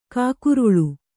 ♪ kākuruḷu